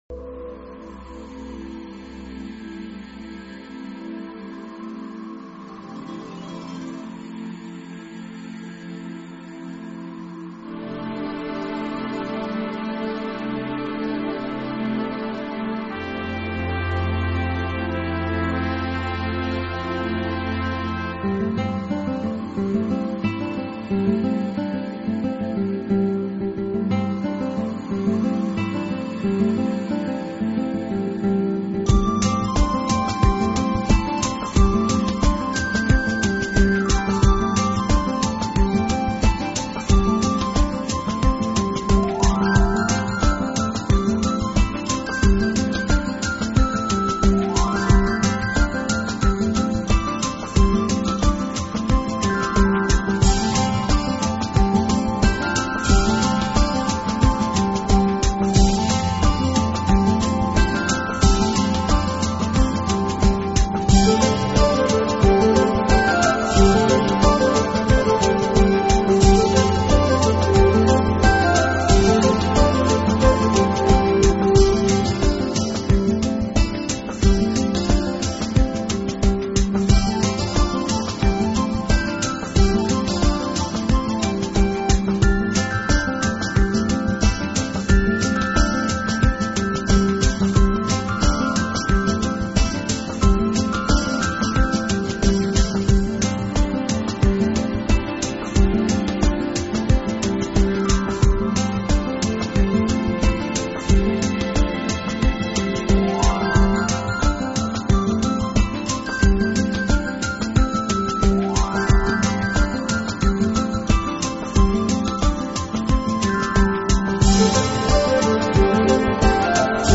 音乐类型：New Age/Angel
美好的仙境……音乐非常净洁纯美，艺术家运用了很多富有美妙声效的电音效果，还
有时下流行的chillout电子节奏，旋律优美，轻快又很有品位的感觉。